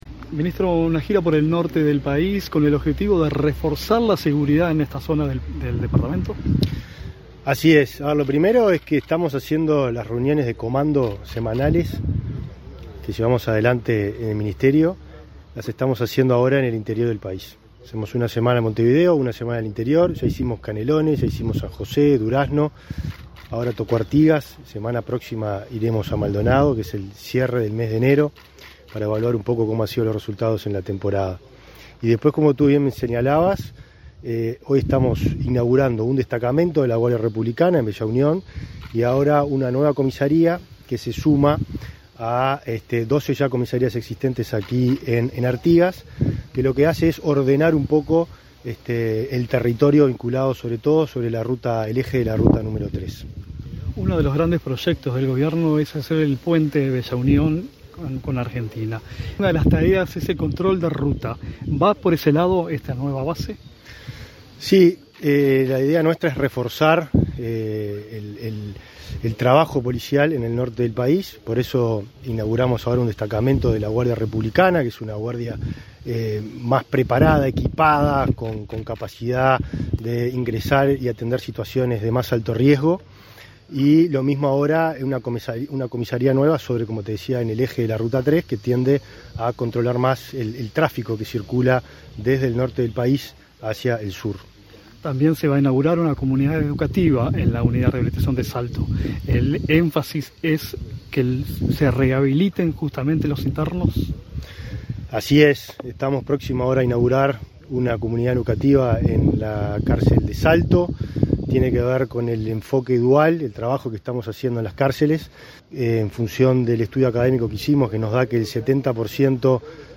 Entrevista al ministro del Interior, Nicolás Martinelli
Entrevista al ministro del Interior, Nicolás Martinelli 23/01/2024 Compartir Facebook X Copiar enlace WhatsApp LinkedIn El ministro del Interior, Nicolás Martinelli, participó, este 23 de enero, en la inauguración de la base Bella Unión de la Dirección Nacional de Guardia Republicana. En entrevista con Comunicación Presidencial, el jerarca explicó el trabajo que se realiza en la zona.